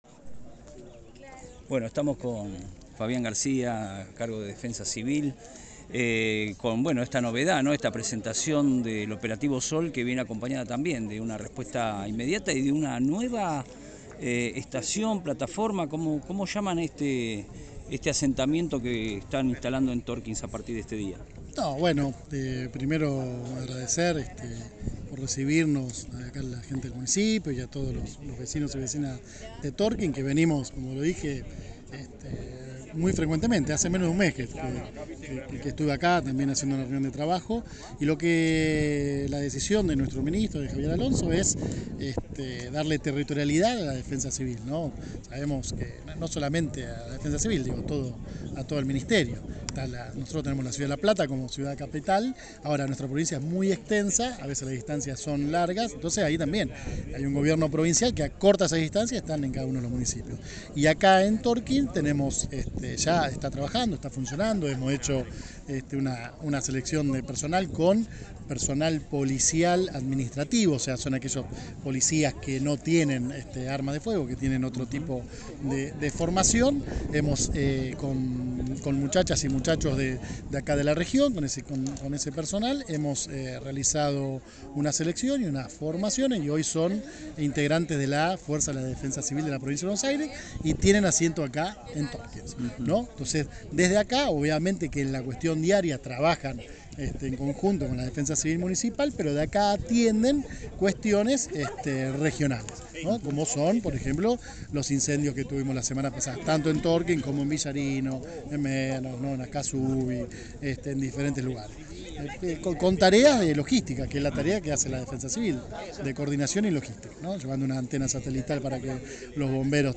En el marco del lanzamiento oficial de la temporada estival en el distrito de Tornquist, el Director Provincial de Defensa Civil, Fabián García, brindó detalles sobre el despliegue de seguridad y prevención que el gobierno bonaerense ha diseñado para este año.
Fabian-Garcia-Defensa-Civil-Bs-As.mp3